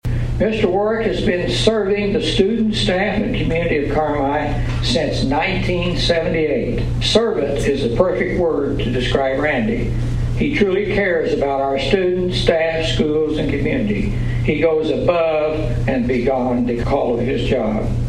This week’s Carmi Kiwanis Club meeting was highlighted by the announcement of the club’s Educator of the year and Support Staff of the year awards.